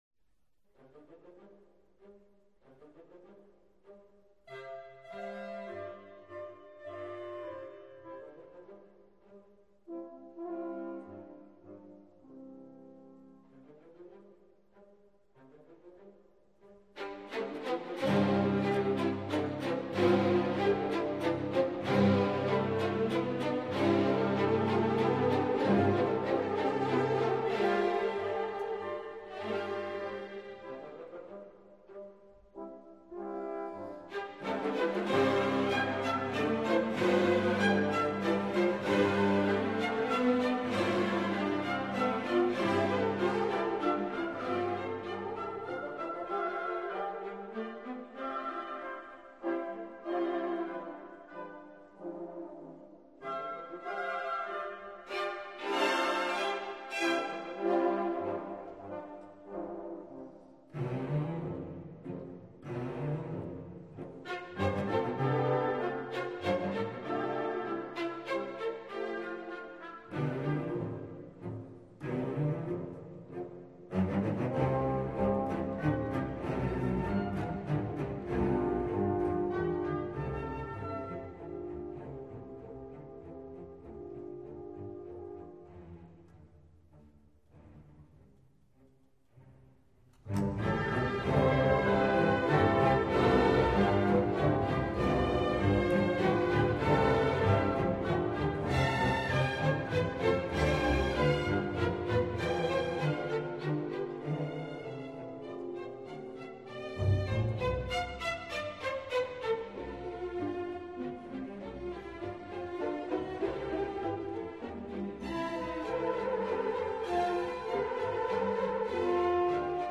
D大调
共4个乐章： 1．悠闲的行板，D大调，扩大的奏鸣曲式。